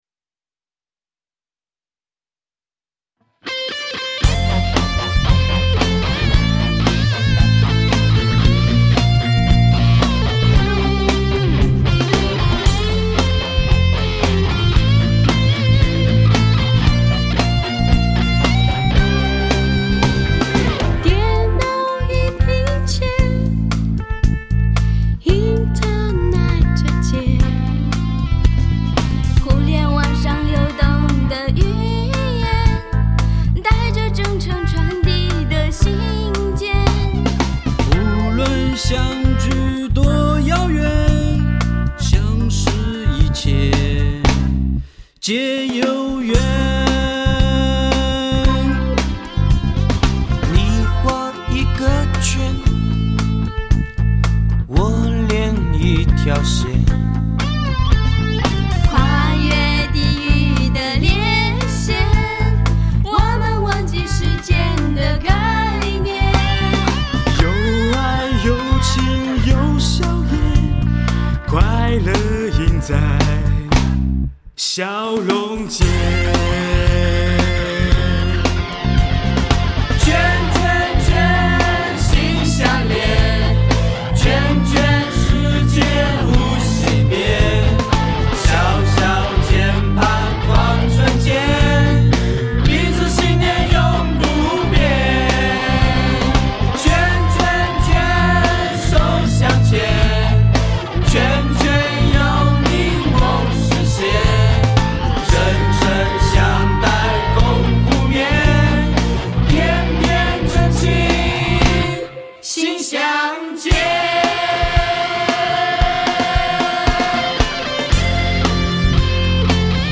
[26/8/2009]这是我们为相约在今天晚会 准备的节目 排练实况录音 激动社区，陪你一起慢慢变老！